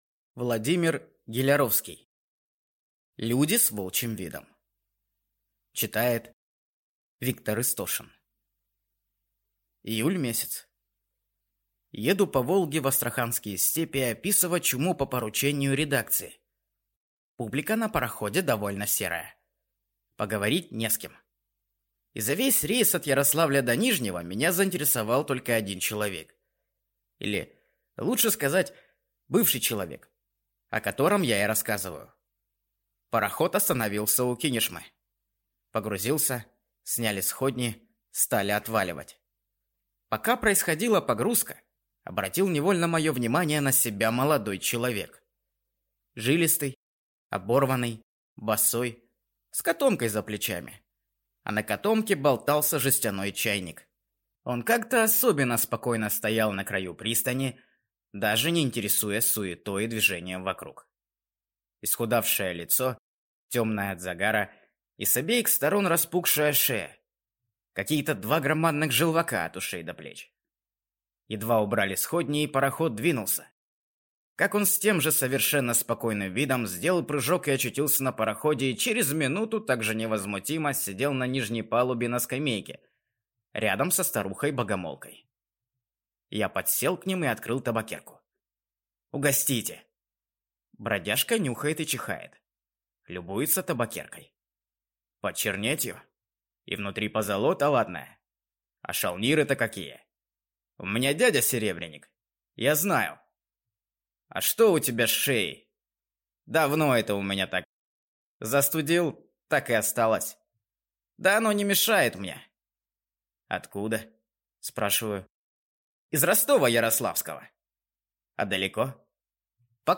Аудиокнига Люди с волчьим видом | Библиотека аудиокниг